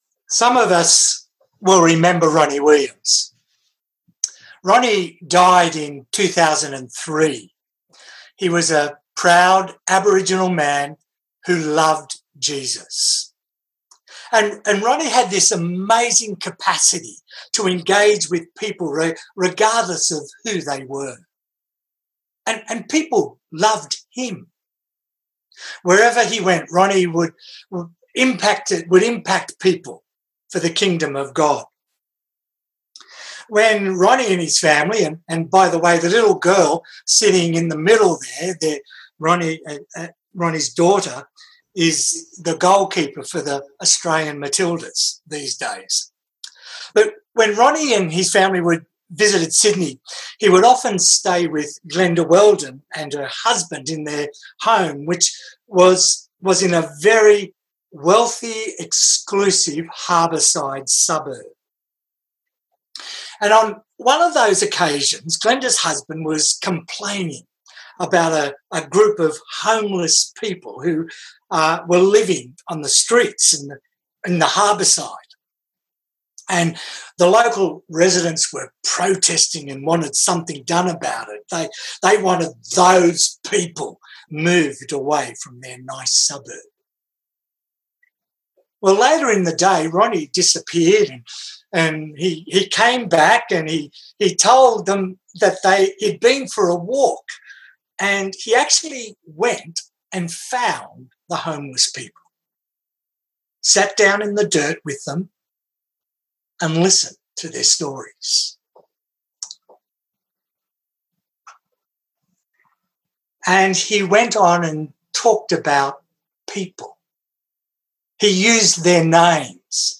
Listen to the sermon. Categories Sermon Tags 2020